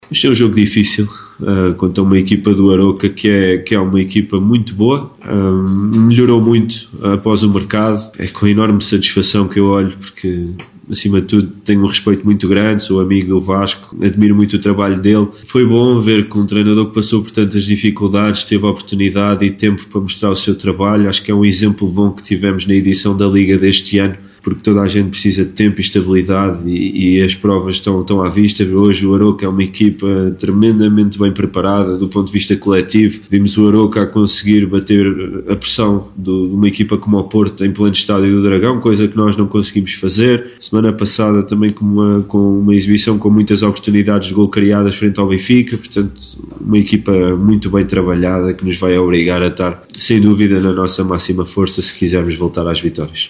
Declarações